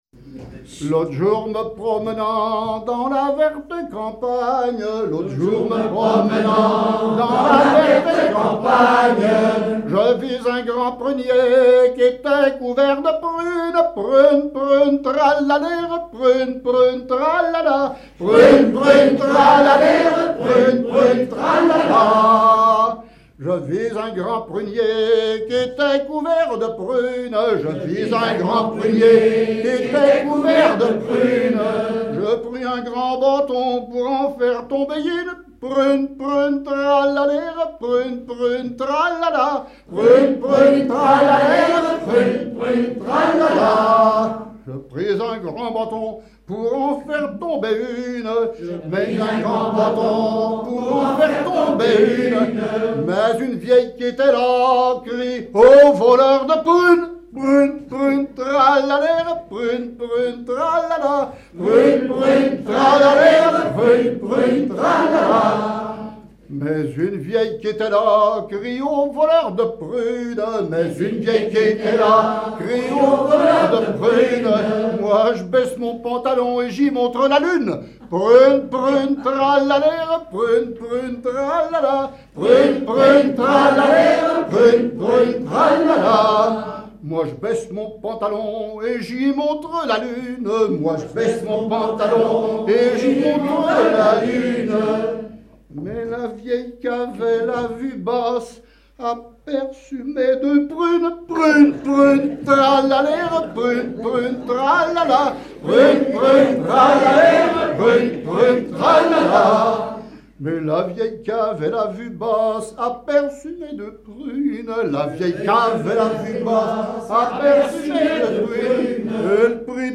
Genre laisse
Veillée
Pièce musicale inédite